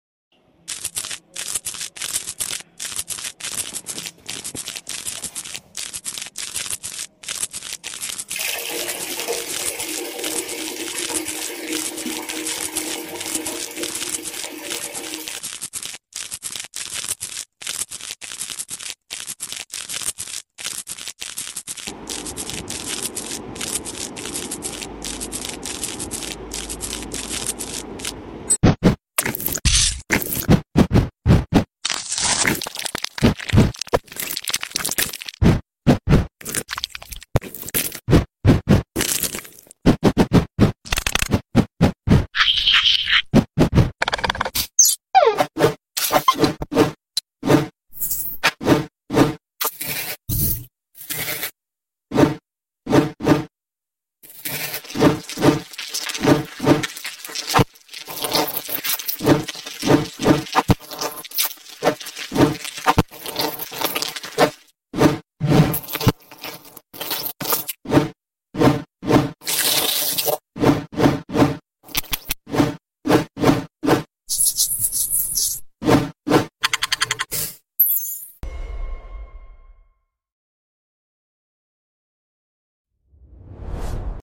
Satisfying ASMR Video's . sound effects free download